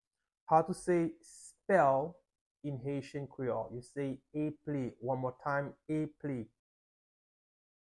Pronunciation:
20.How-to-say-Spell-in-Haitian-Creole-eple-with-pronunciation.mp3